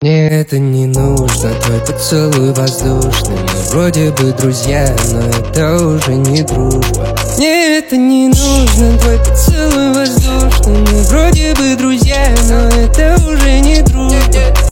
Русские песни
• Качество: 321 kbps, Stereo